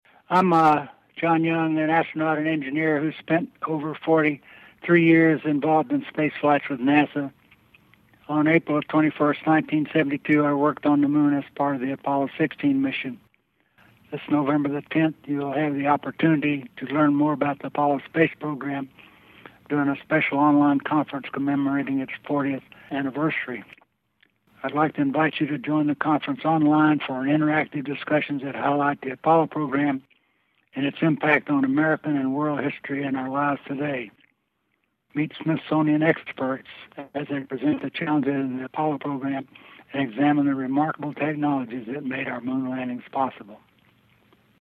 John Young Listen to a personal invitation
from space pioneer John Young: